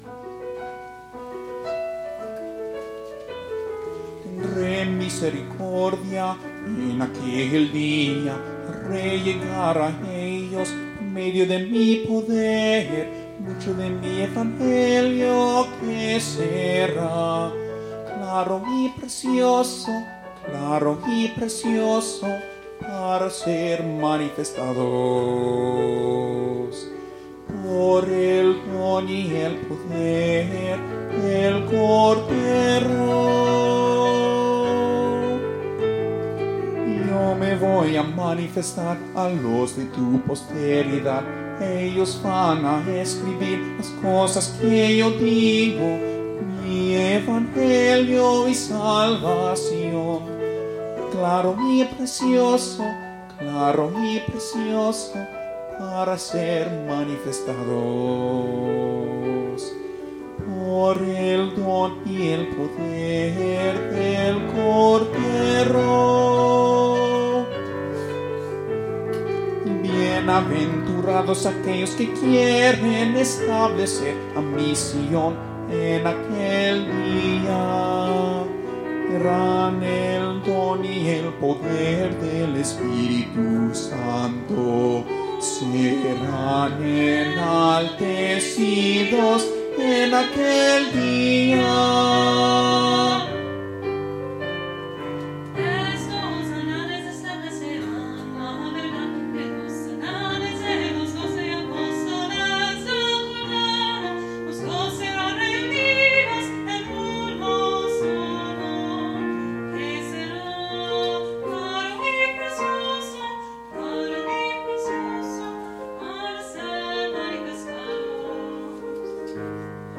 Alto, Bass, SATB Choir, and Piano